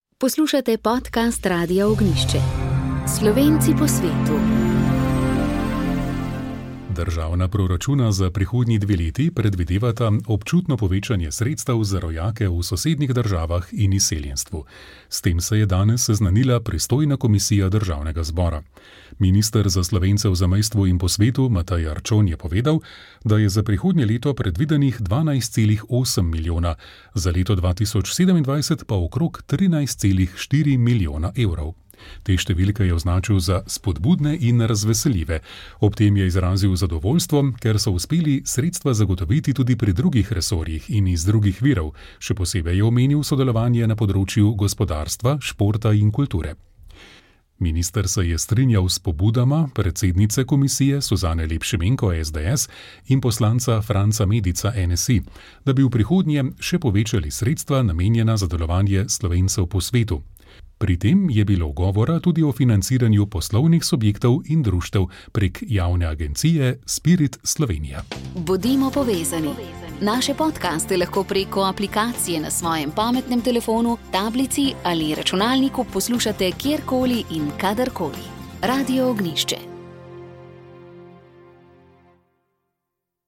V Sobotnem duhovnem večeru ste slišali duhovni nagovor koprskega škofa Jurija Bizjaka. Nadaljevali smo z molitvijo prvih nedeljskih večernic 28. nedelje med letom in rožnim vencem, s katerim smo molili po namenu papeža Frančiška za oktober - za sinodo. V tokratni katehezi smo se spomnili Ignacija Antiohijskega, ki je povezan tudi z Radiem Ognjišče.